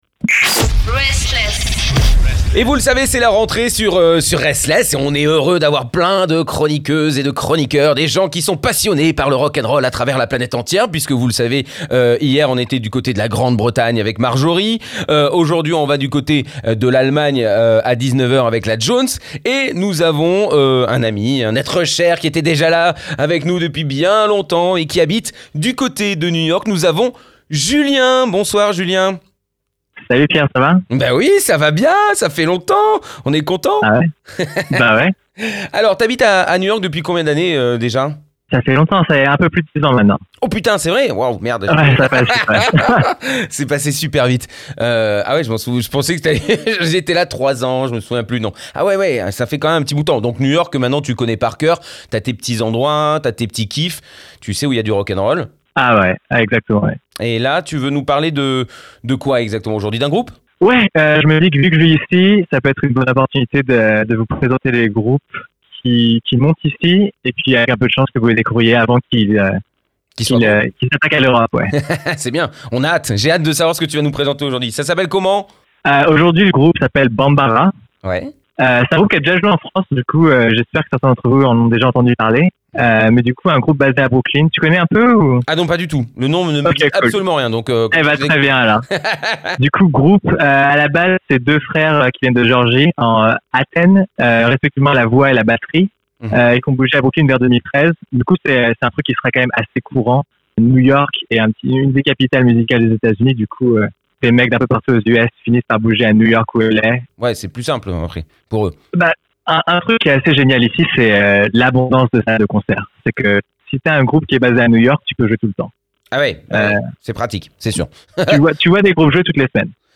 C’est du post-punk. Il y a de la reverb, il y a du feedback. C’est sombre, ça a une aura.